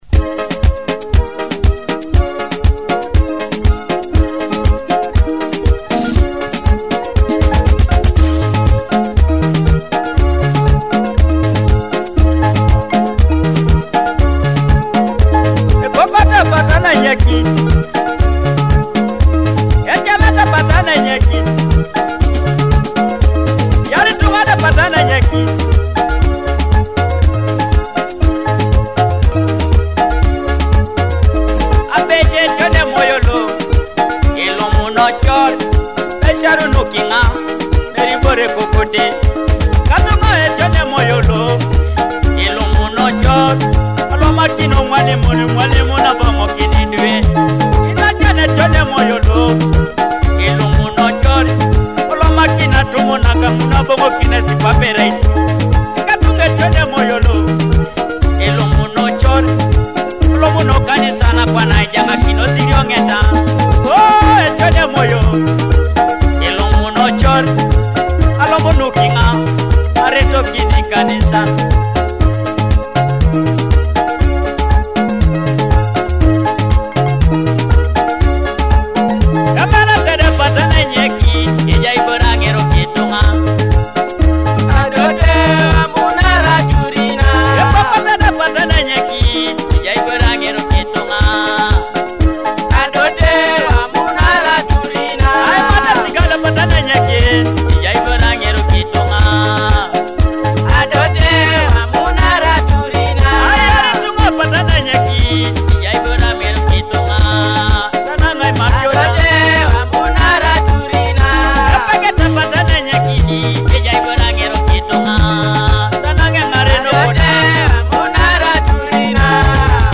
joyful Ateso cultural and traditional rhythms
Akogo (thumb piano)
Adungu (arched harp)